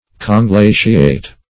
Search Result for " conglaciate" : The Collaborative International Dictionary of English v.0.48: Conglaciate \Con*gla"ci*ate\ (?; 221), v. t. & i. [L. conglaciatus, p. p. of conglaciare.